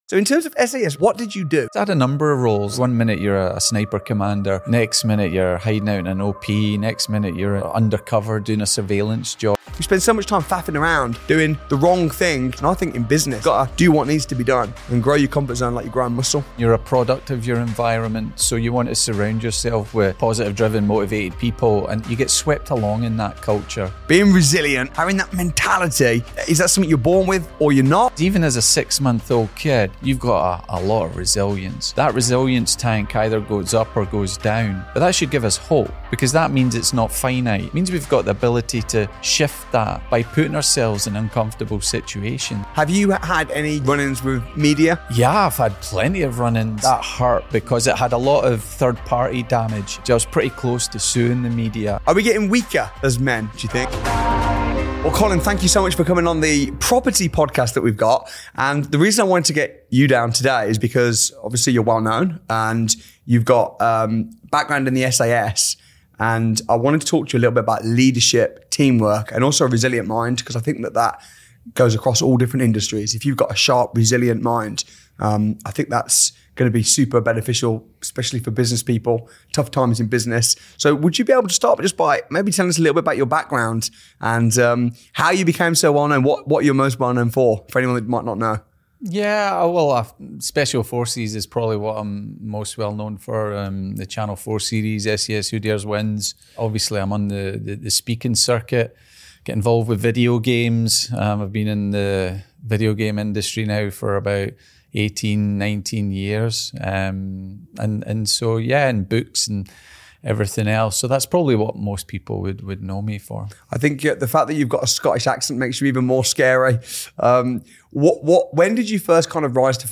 You Need To Build Resilience To Be Successful | Interview with SAS' Colin Maclachlan